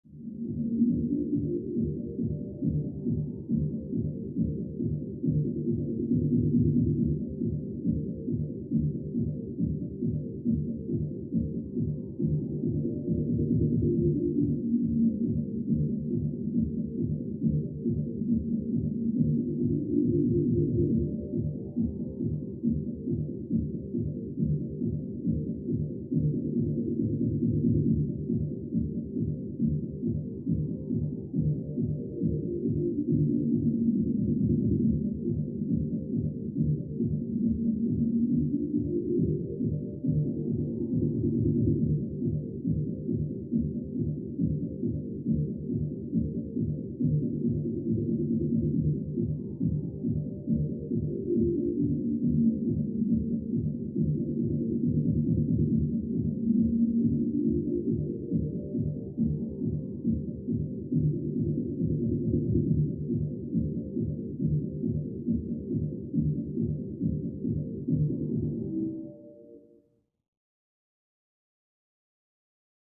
Music; Electronic Dance Beat, Through Thick Wall.